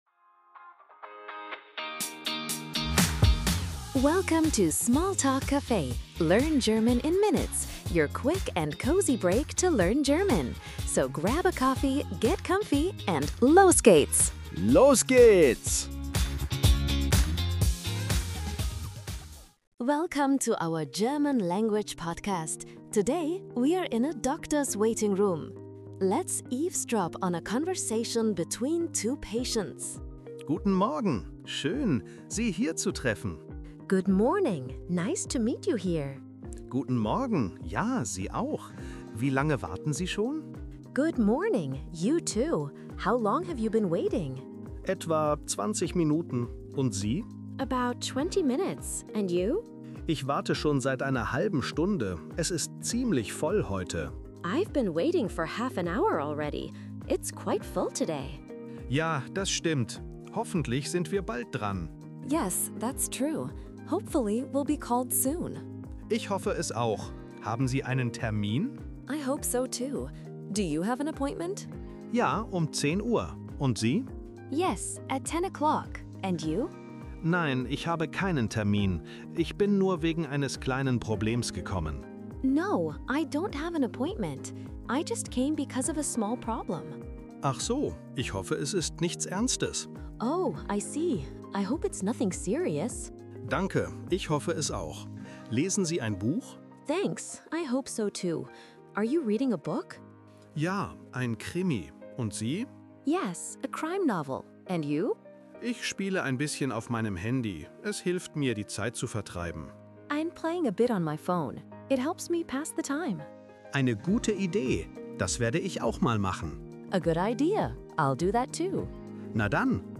Learning German in a relaxed waiting room? Simple conversations, easy to follow!